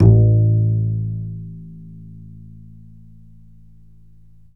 DBL BASS BN1.wav